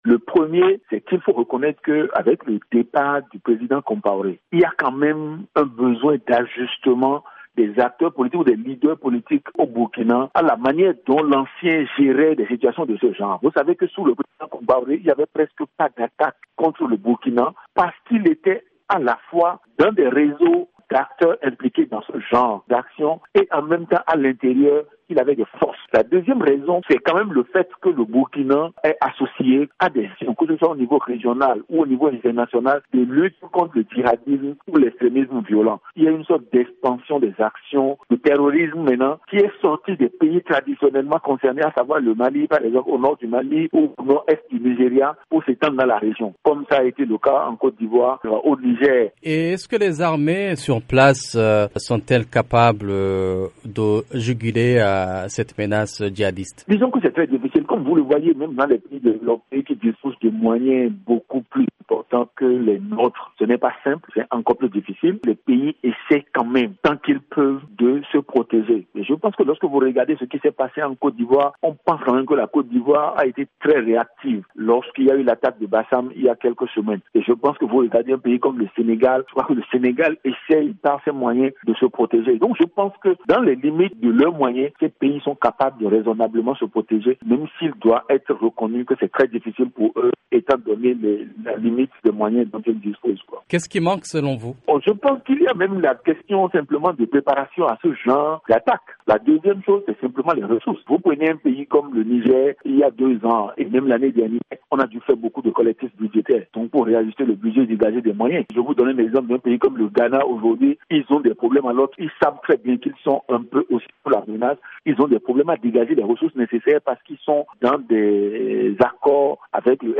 En Afrique de l’Ouest, plusieurs facteurs contribuent à l’expansion du terrorisme selon un politologue joint par VOA Afrique.